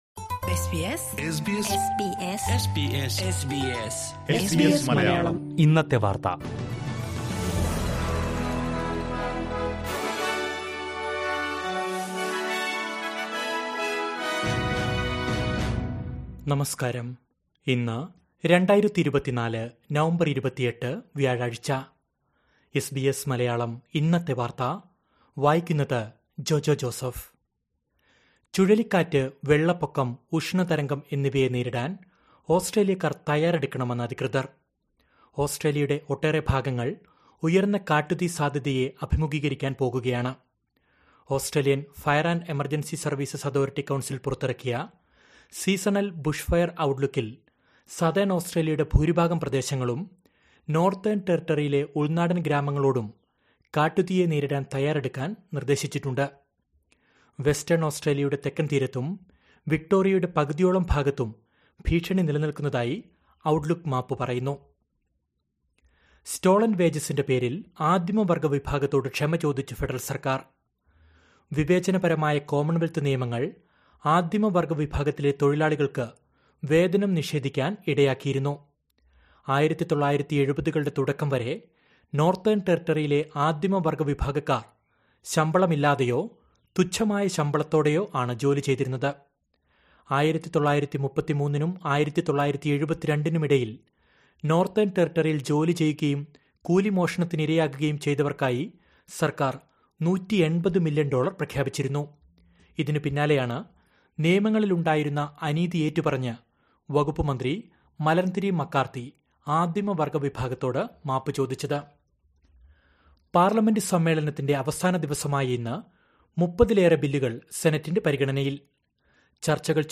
2024 നവംബർ 28ലെ ഓസ്ട്രേലിയയിലെ ഏറ്റവും പ്രധാന വാർത്തകൾ കേൾക്കാം...